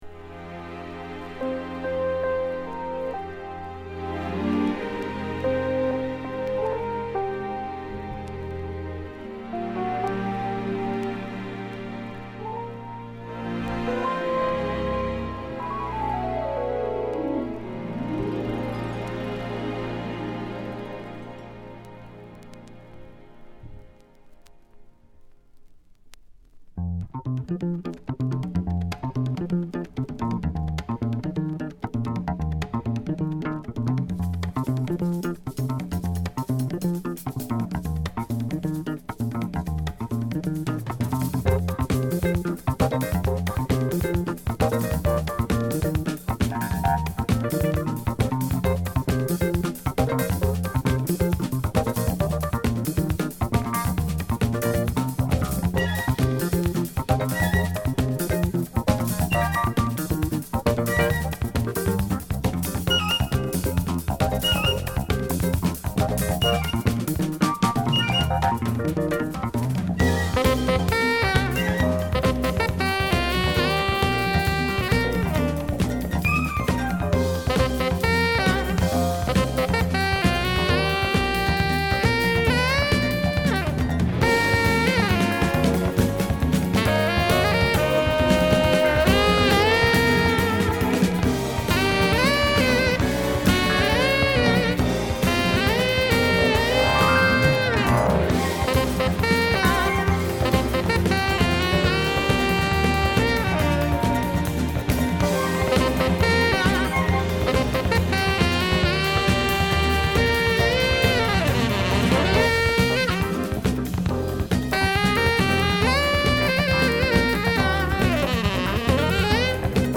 スピリチュアルなジャズSax奏者